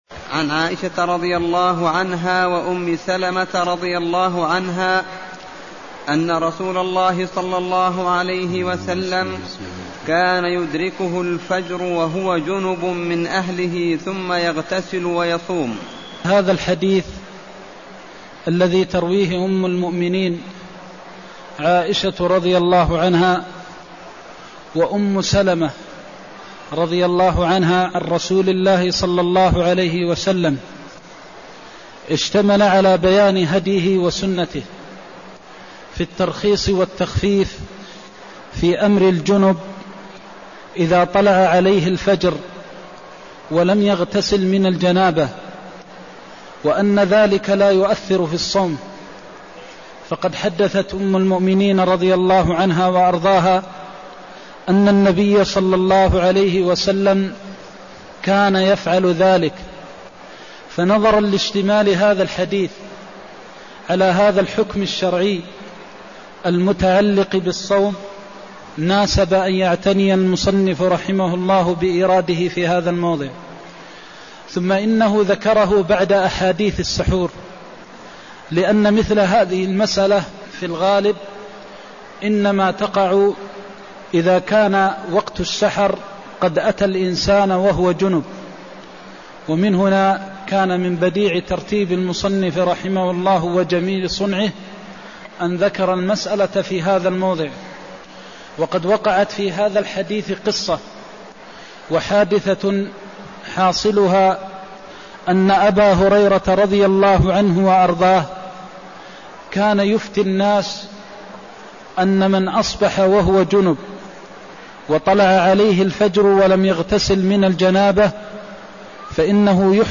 المكان: المسجد النبوي الشيخ: فضيلة الشيخ د. محمد بن محمد المختار فضيلة الشيخ د. محمد بن محمد المختار حكم الصائم يصبح جنباً (174)